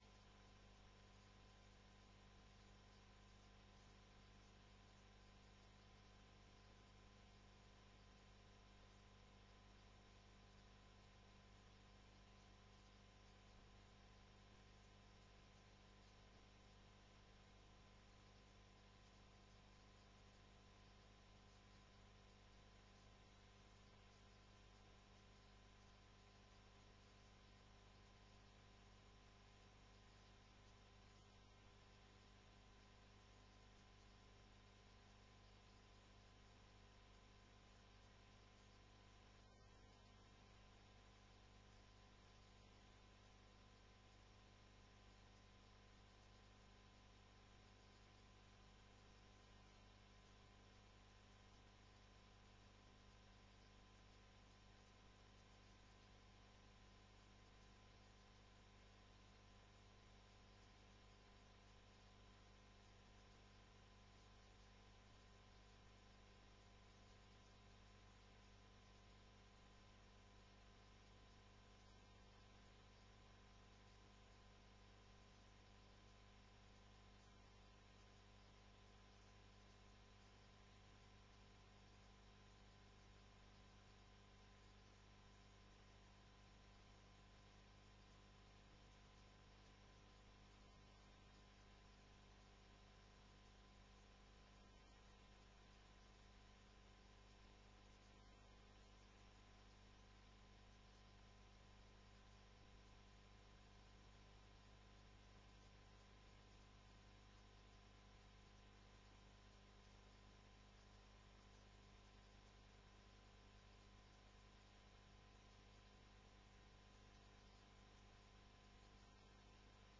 Werkvergadering 18 november 2010 19:30:00, Gemeente Beemster
Locatie: Raadzaal